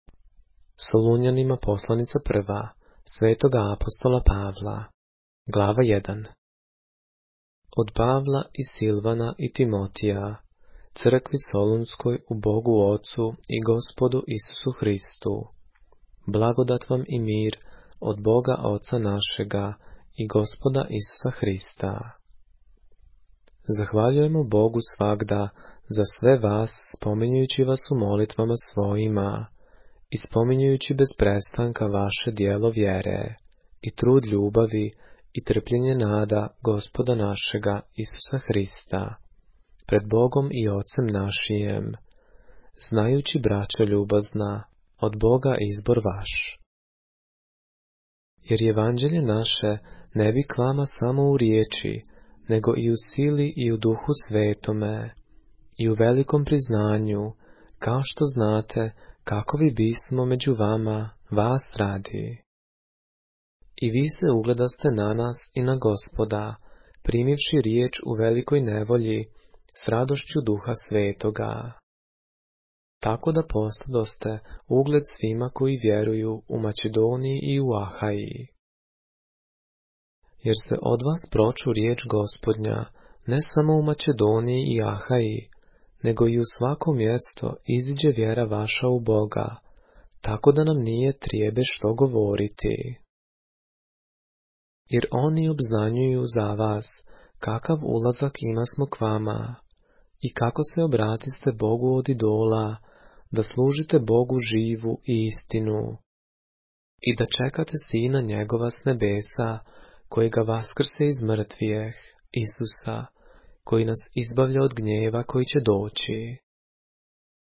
поглавље српске Библије - са аудио нарације - 1 Thessalonians, chapter 1 of the Holy Bible in the Serbian language